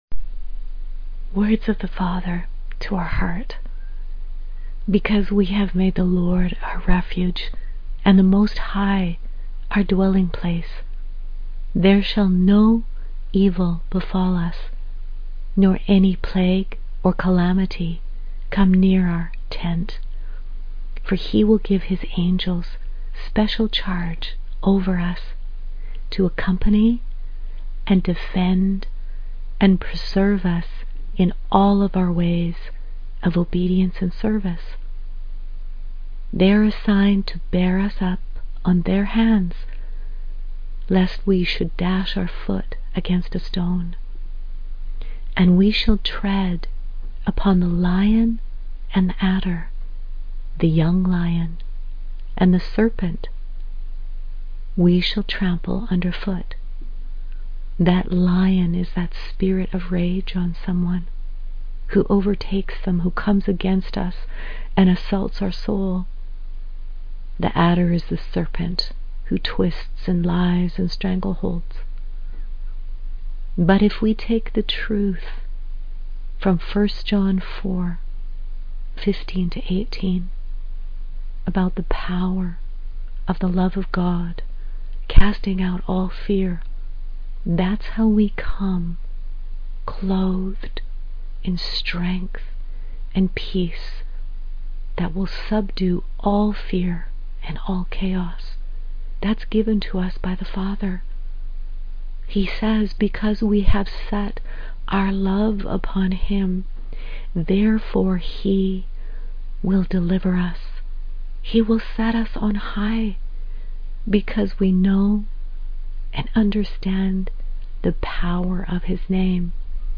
— Audio verse and prayer — Psalm 91:9-15 —